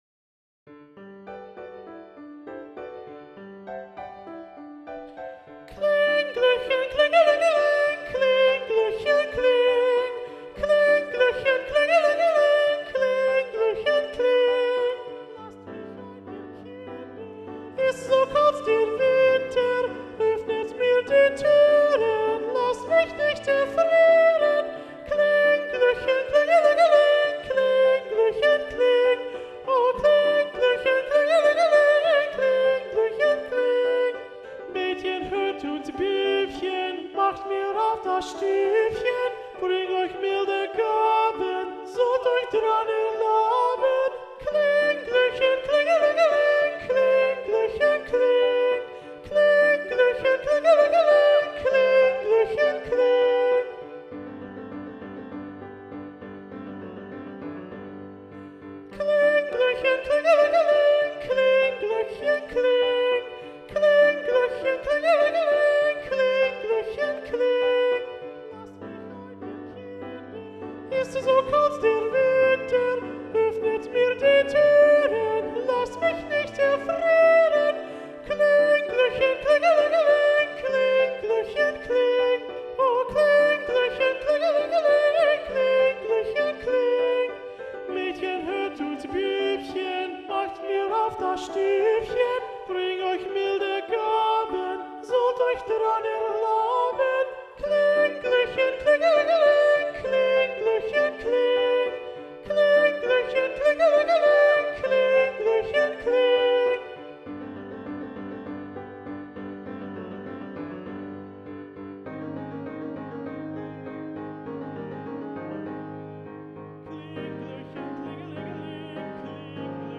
– Soprano 2 Predominant – arr. Robert Sieving